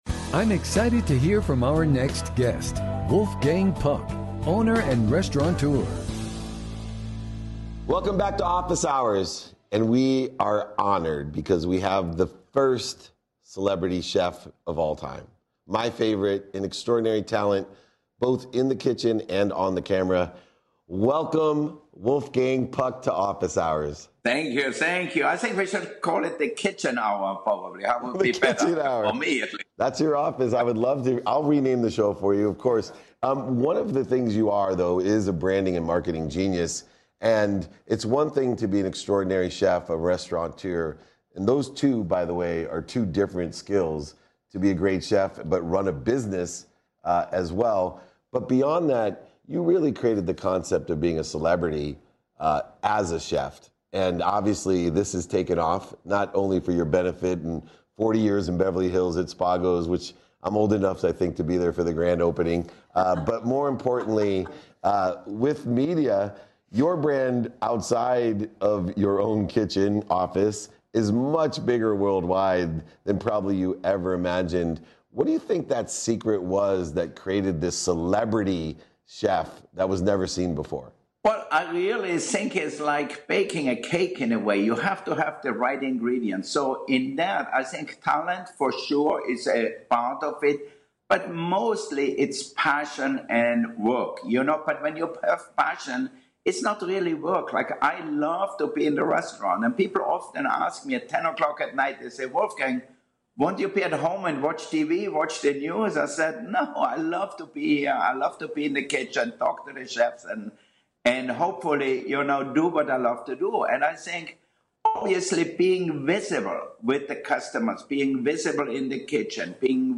Get things cooking with this clip from Season Two episode of Office Hours featuring legendary chef and restaurateur Wolfgang Puck dishing his insights on brand building and how he handled being fired from his first restaurant job.